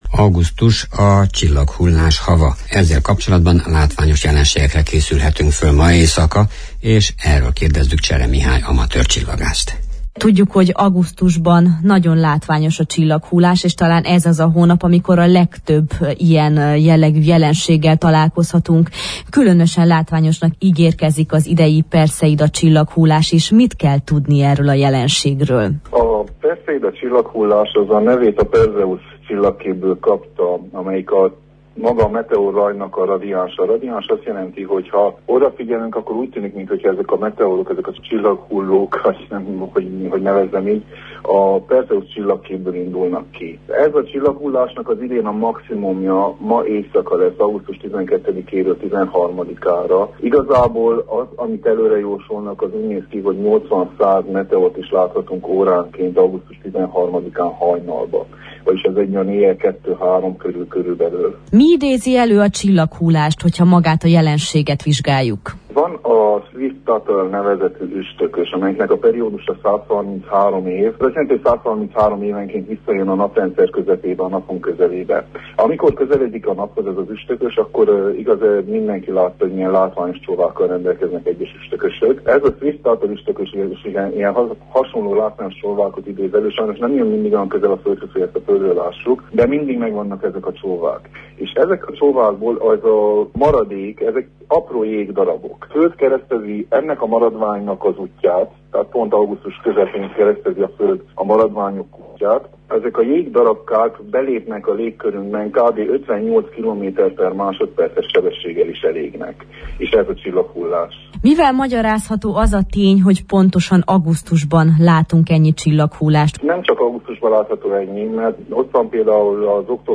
A szakembert kérdeztük a különleges jelenségről.